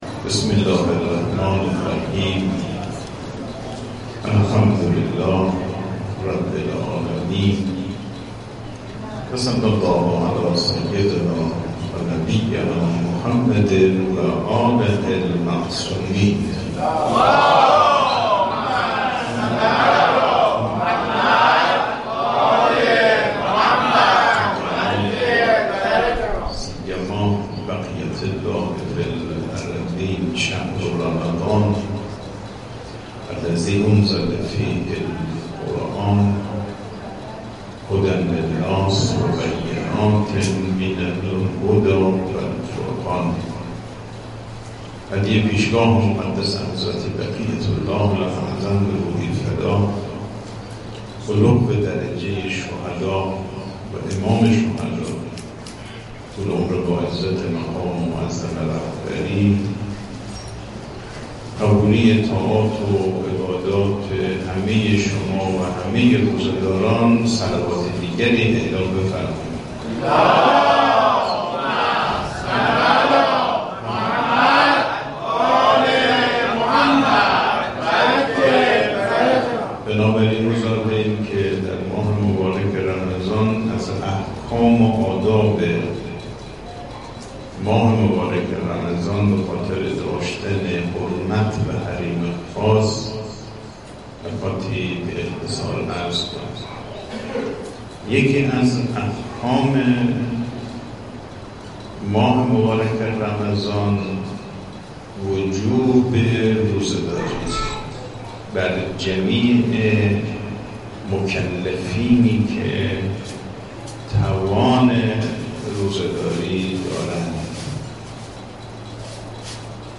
صوت | درس اخلاق حجت‌الاسلام والمسلمین صفایی بوشهری در مسجد امام حسن مجتبی (ع)
حوزه/ سلسله جلسات درس اخلاق حجت‌الاسلام والمسلمین صفایی بوشهری نماینده ولی‌فقیه در استان بوشهر در ماه مبارک رمضان در مسجد امام حسن مجتبی علیه السلام بوشهر بعد از نماز ظهر و عصر در حال برگزاری است.